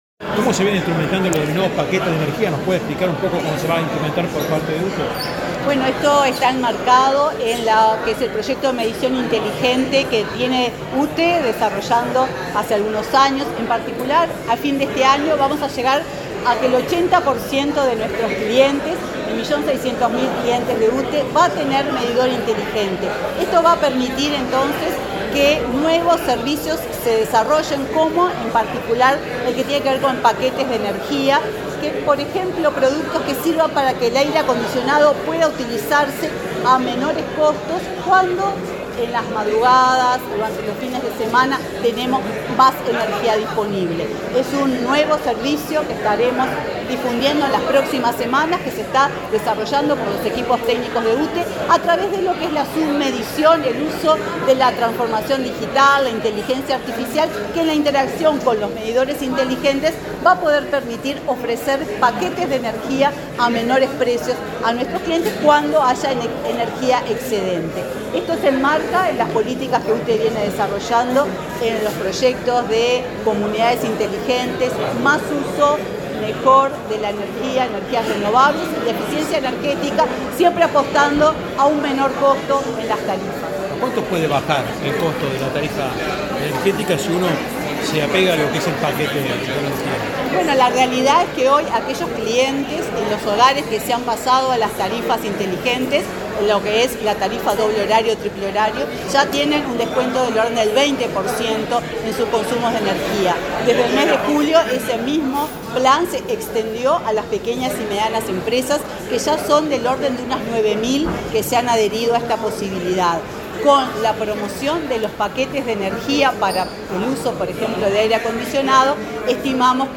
Declaraciones de la presidenta de UTE, Silvia Emaldi
Luego, dialogó con la prensa.